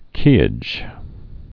(kēĭj, kā-, kwā-)